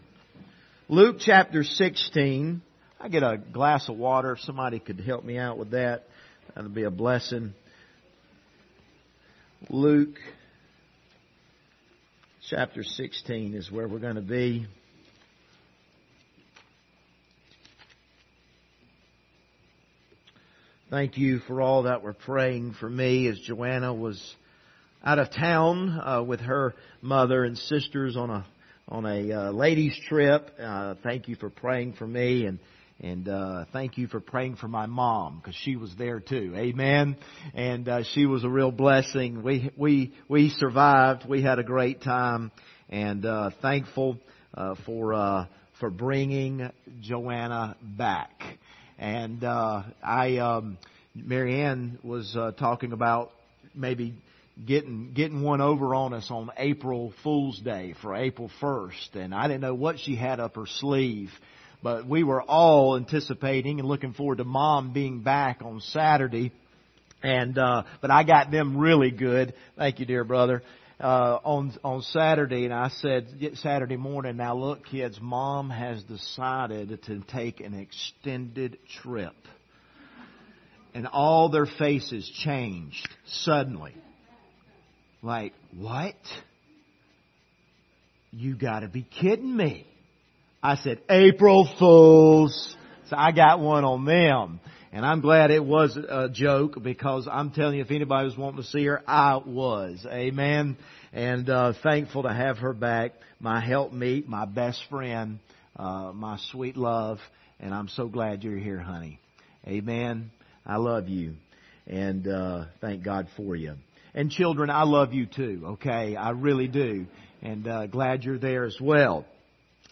Passage: Luke 16:19-31 Service Type: Sunday Morning View the video on Facebook « Protectors of the Peace of God